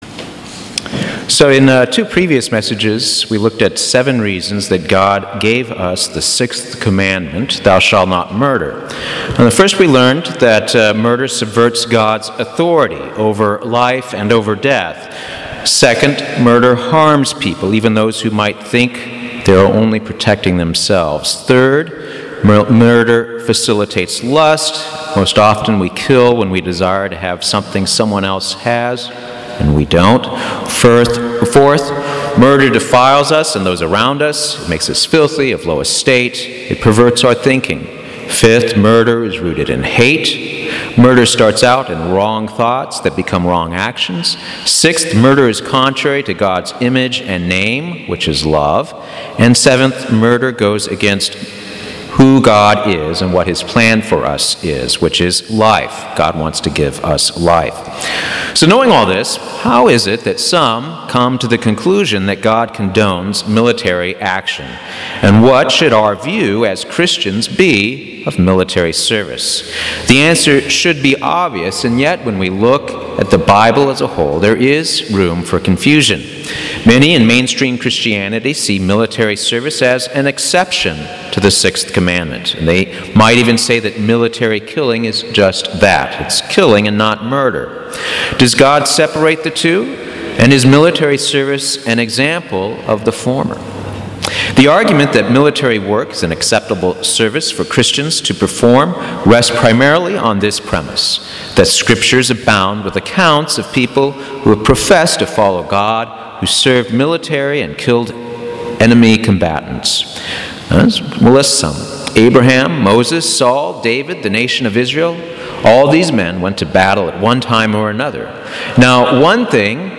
God's true view of war, even by His chosen people, is explained in this split-sermon.